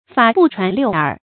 法不傳六耳 注音： ㄈㄚˇ ㄅㄨˋ ㄔㄨㄢˊ ㄌㄧㄨˋ ㄦˇ 讀音讀法： 意思解釋： 指極端秘密，不能讓第三者知道。